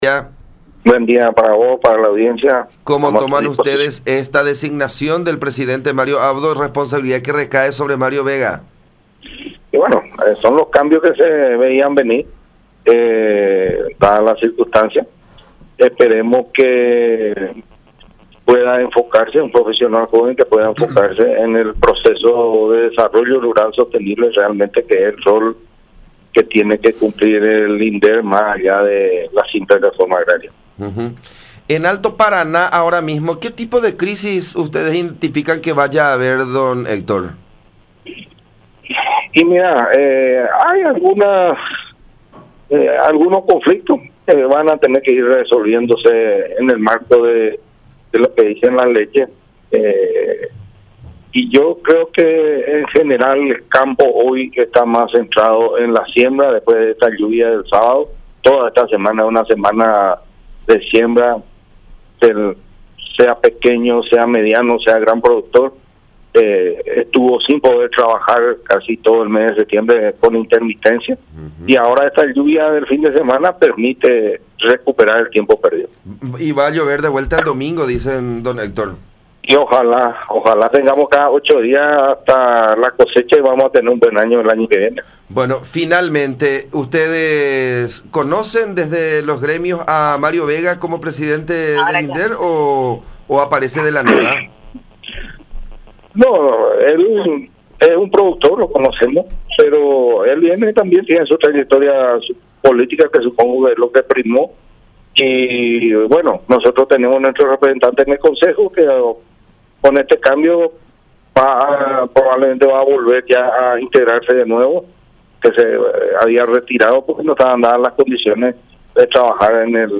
en comunicación con La Unión